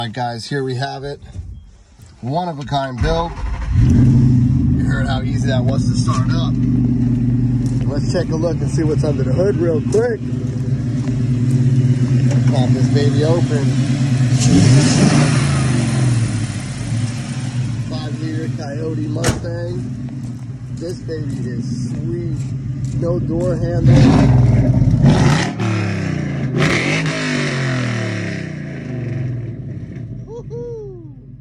1967 Mustang With A 5.0L Sound Effects Free Download